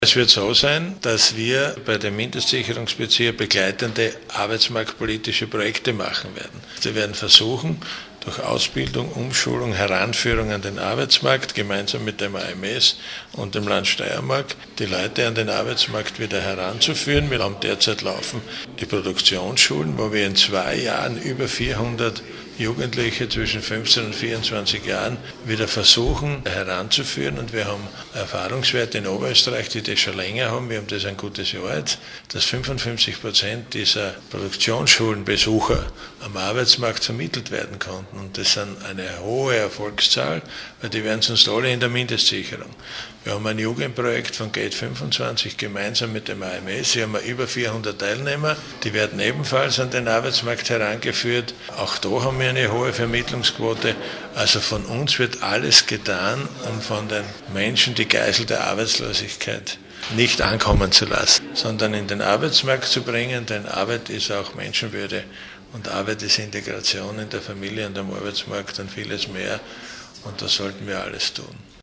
Soziallandesrat LH-Stv. Siegfried Schrittwieser:
Statement